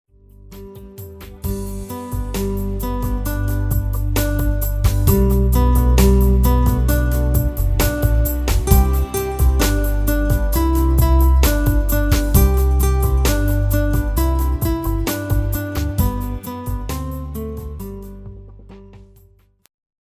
slovenské koledy v ľahkej úprave pre klavír